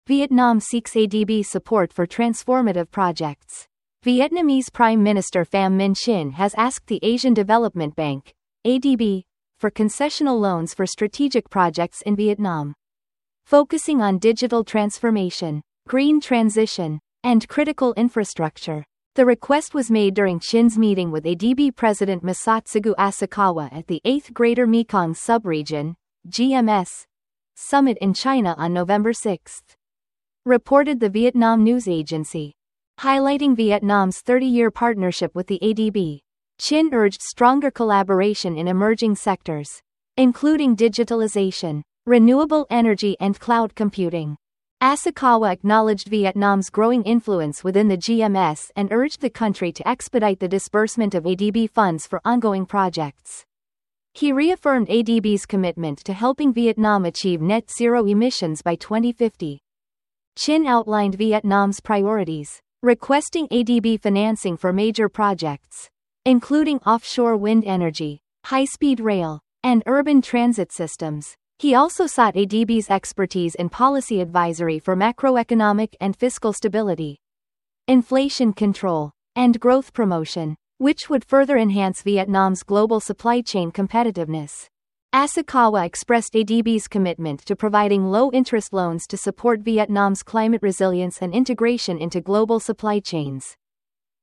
419029--en-US-Wavenet-F.mp3